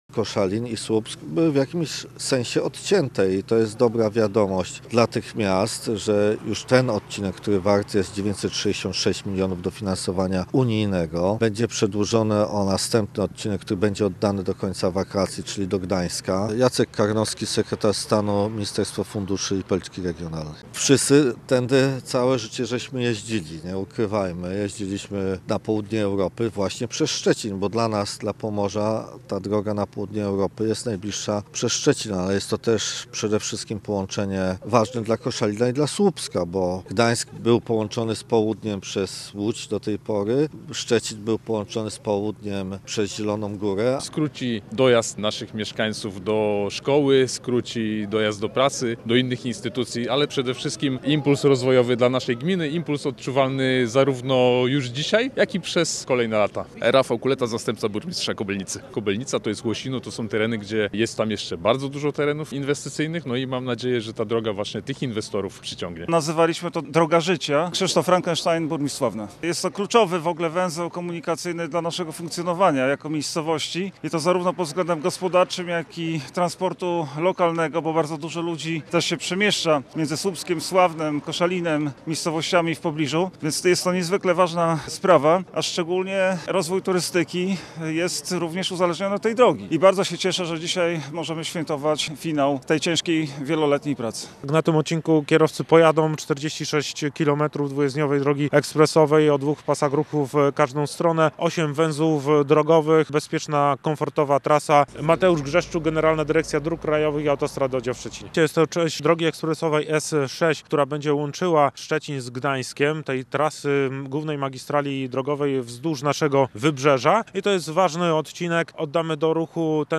Do uroczystego otwarcia nowej trasy doszło na węźle Sycewice, który znajduje się dokładnie na granicy województw pomorskiego i zachodniopomorskiego.